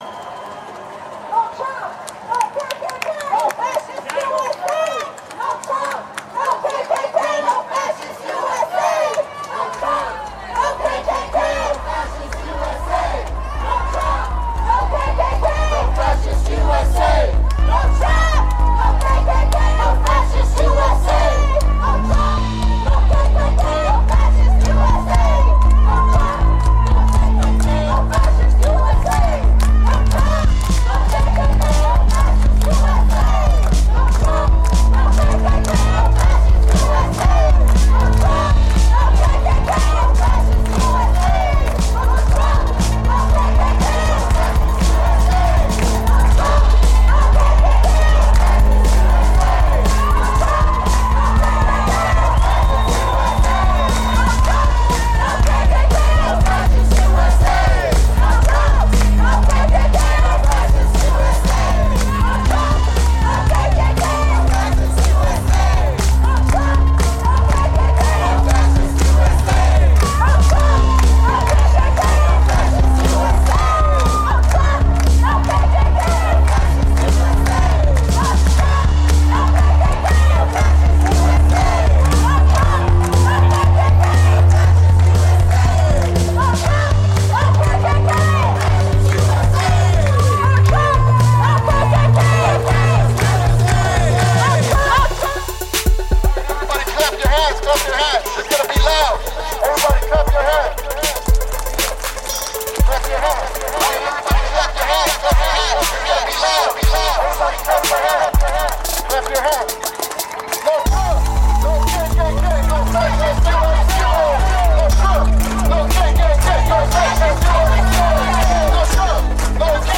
Anti-Trump protest reimagined